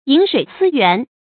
注音：ㄧㄣˇ ㄕㄨㄟˇ ㄙㄧ ㄧㄨㄢˊ
飲水思源的讀法